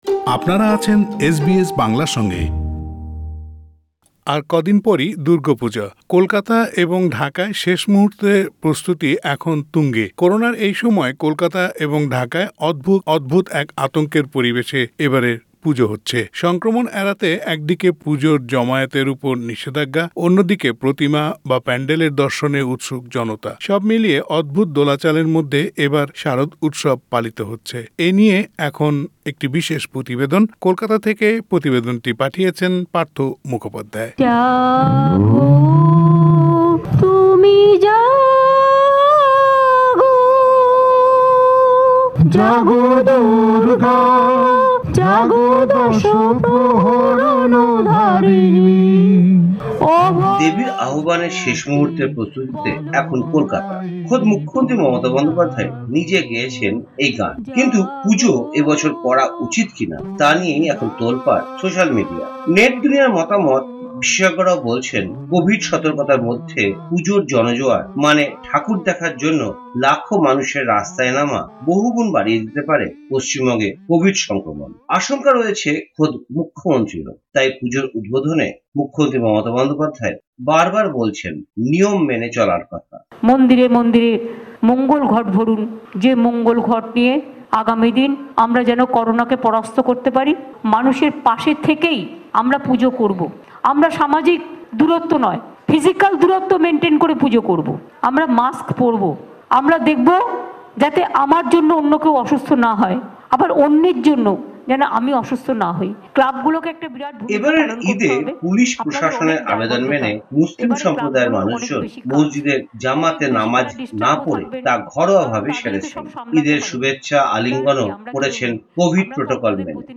প্রতিবেদনটি শুনতে উপরের অডিও ক্লিপের লিংকটিতে ক্লিক করুন।